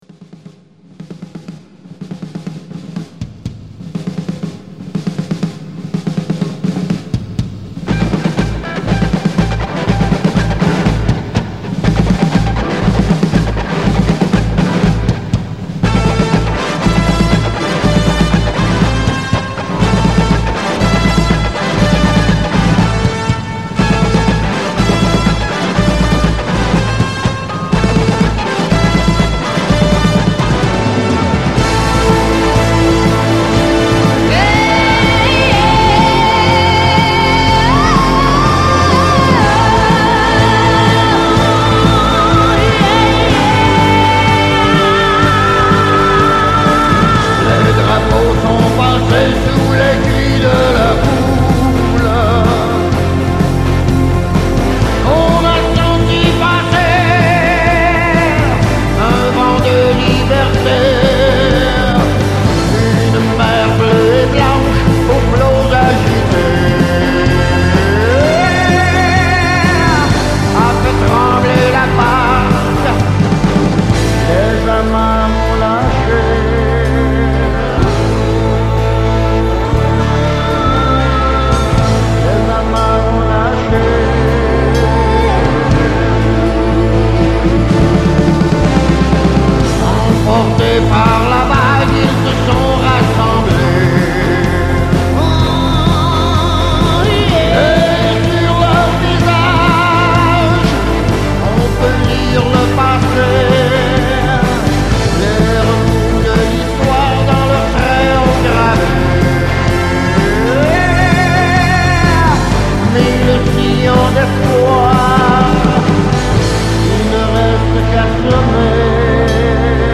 guitare, harmonica et voix
batterie
guitare basse
flûte traversière